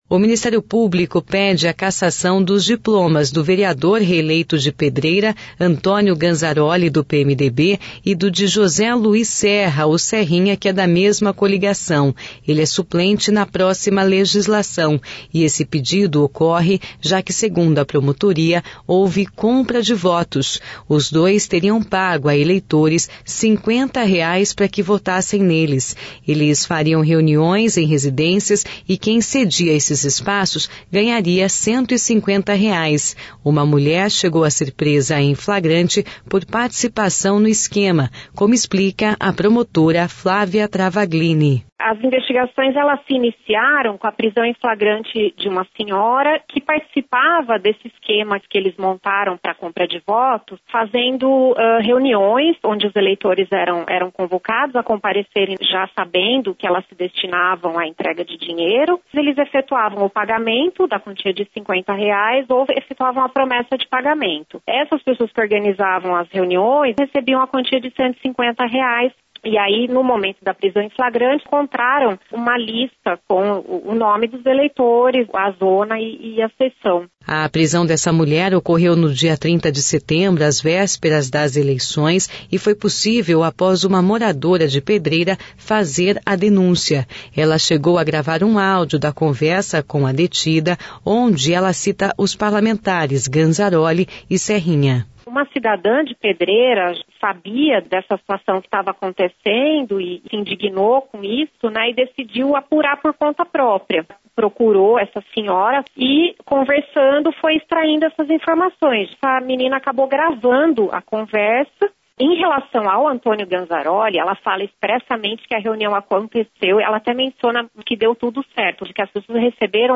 Uma mulher chegou a ser presa em flagrante por participação no esquema como explica a promotora Flávia Travaglini.